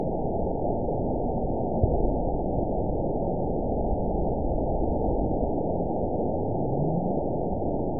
event 912564 date 03/29/22 time 12:37:31 GMT (3 years, 1 month ago) score 9.59 location TSS-AB04 detected by nrw target species NRW annotations +NRW Spectrogram: Frequency (kHz) vs. Time (s) audio not available .wav